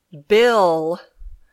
This page: Pronounciation of the phonetic sounds /I/ and /e/